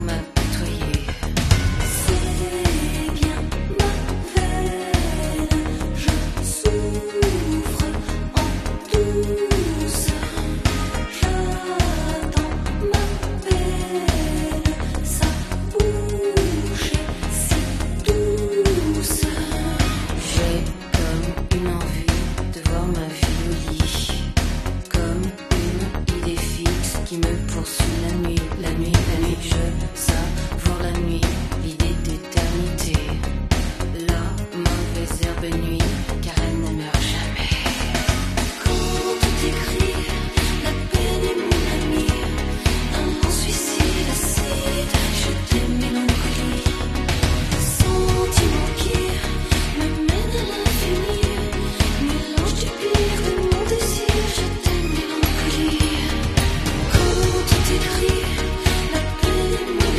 German TV 1991